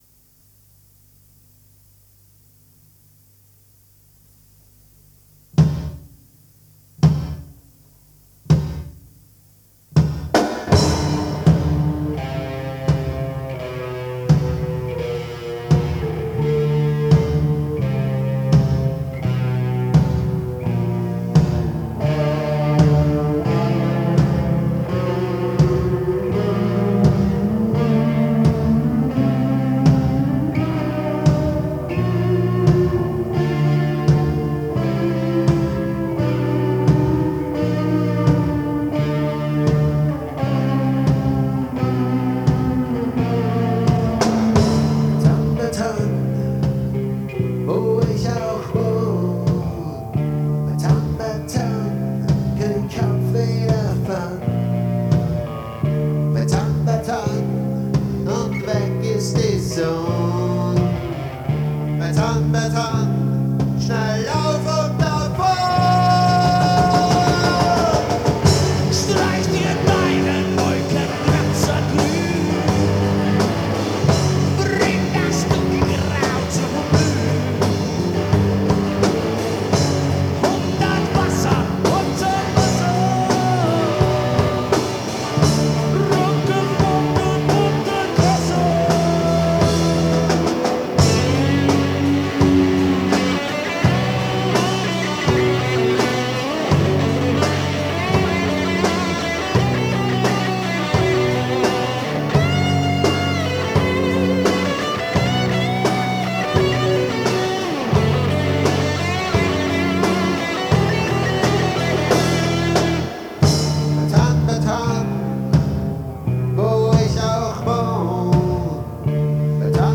Genre:   Freie Musik - Austro-Pop